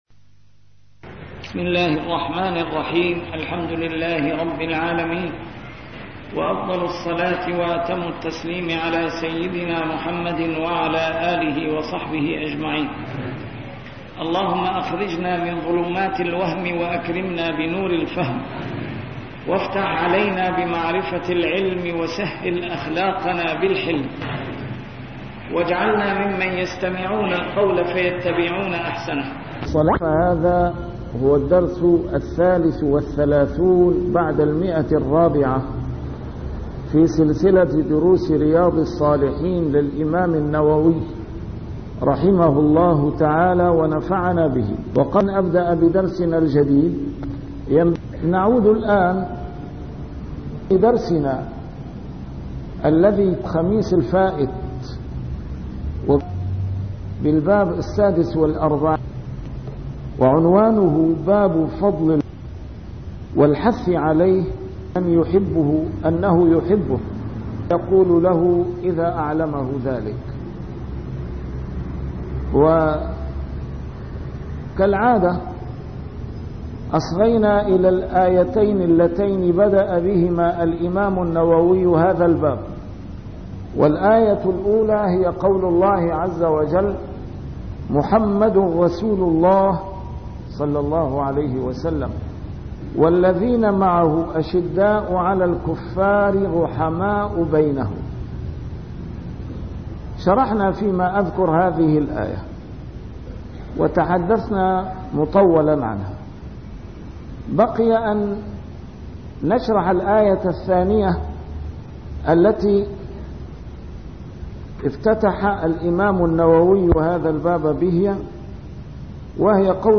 A MARTYR SCHOLAR: IMAM MUHAMMAD SAEED RAMADAN AL-BOUTI - الدروس العلمية - شرح كتاب رياض الصالحين - 433- شرح رياض الصالحين: فضل الحب في الله